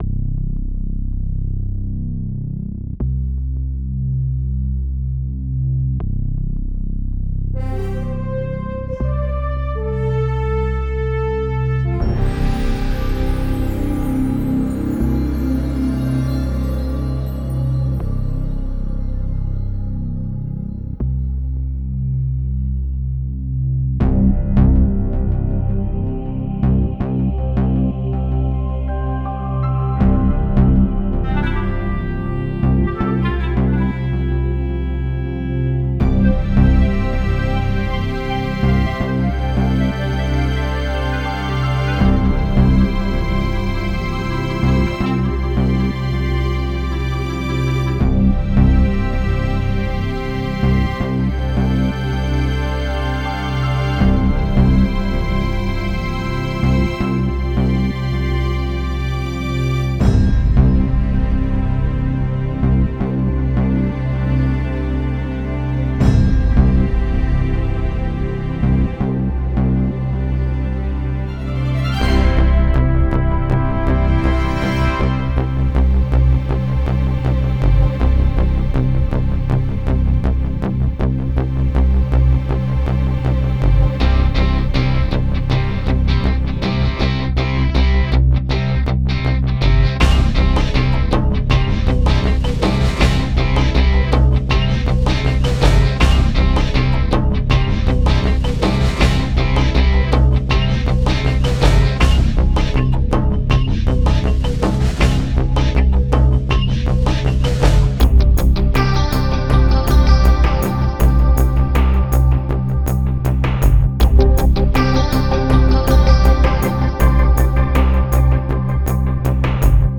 Ihr erinnert Euch an das Kalenderblatt vom 30 Oktober ... eine instrumentale
Meine Meinung: bis zur Mitte genial, durch die Gitarren muss man mühsam durch und der Schluss ist dann wieder in Ordnung.
Vor allem: Klassische Momente mit "Computermusik" gemischt.
- ist sicher mit dem Computer gemacht.